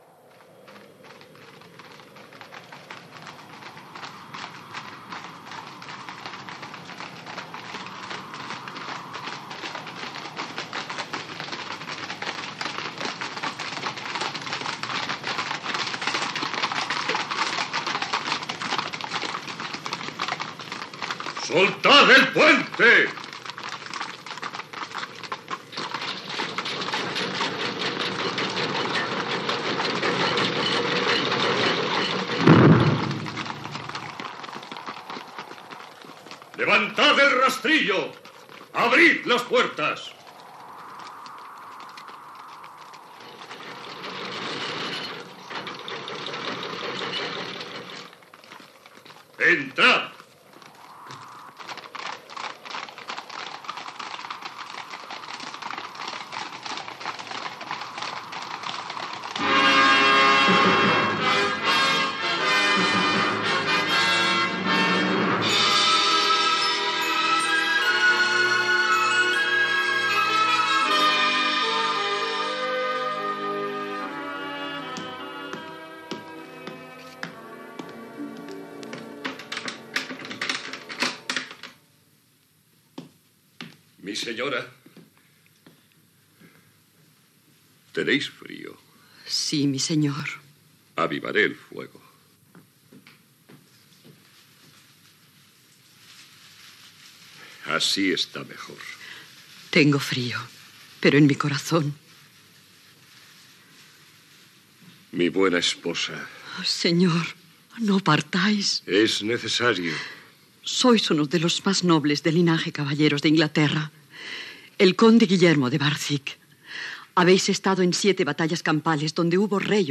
Fragment de l'adaptació radiofònica de "Tirant lo blanc" de Joanot Martorell.
Ficció